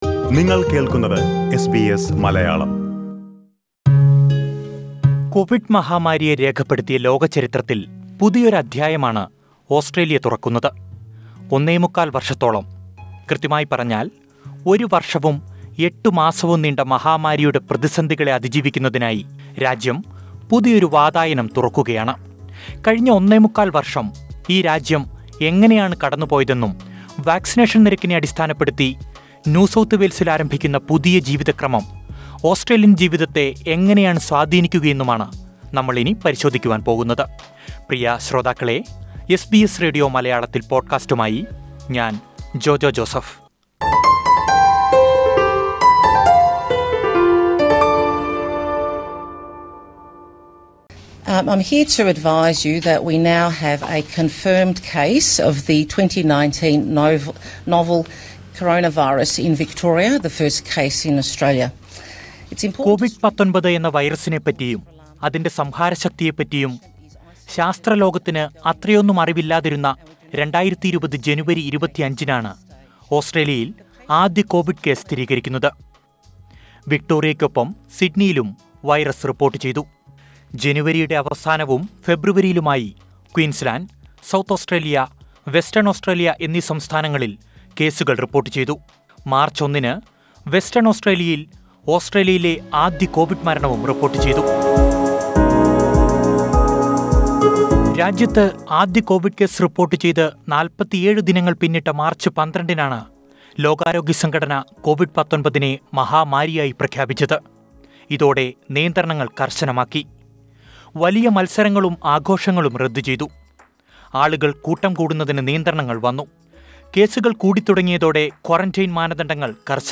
വാക്സിനേഷൻ നിരക്കിനെ അടിസ്ഥാനപ്പെടുത്തി പ്രഖ്യാപിച്ചിരിക്കുന്ന ഇളവുകൾ നടപ്പിലാക്കുന്ന ഓസ്ട്രേലിയയിലെ ആദ്യ സംസ്ഥാനമായി ന്യൂ സൗത്ത് വെയിൽസ് മാറിയിരിക്കുകയാണ്. കൊവിഡ് പ്രതിരോധത്തിൽ ഓസ്ട്രേലിയ പിന്നിട്ട വഴികളെപ്പറ്റിയും, കൊവിഡിനൊടൊപ്പമുള്ള പുതിയ ജീവിത ക്രമത്തെപ്പറ്റിയും ഓസ്ട്രേലിയൻ മലയാളികൾ പ്രതീക്ഷകളും അനുഭവങ്ങളും പങ്കുവെക്കുന്നത് കേൾക്കാം, മുകളിലെ പ്ലെയറിൽ നിന്നും...